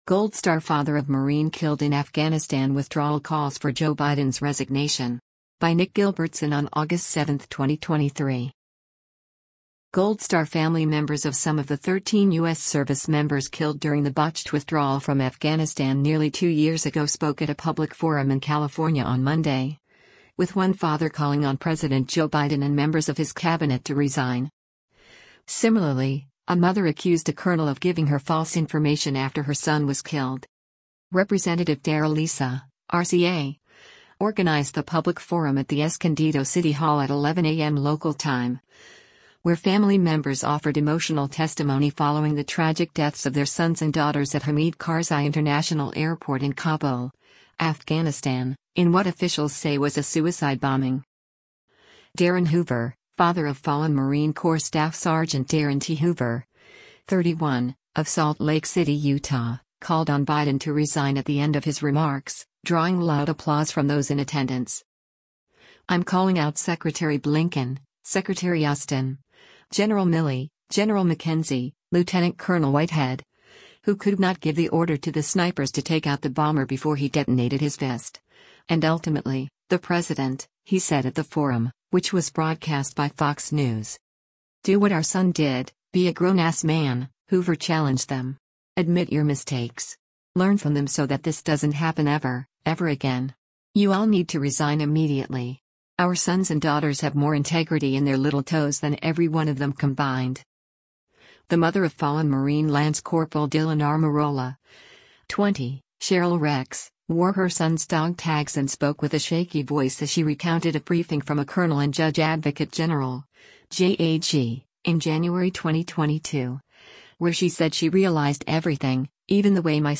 Gold Star family members of some of the 13 U.S. service members killed during the botched withdrawal from Afghanistan nearly two years ago spoke at a public forum in California on Monday, with one father calling on President Joe Biden and members of his cabinet to resign.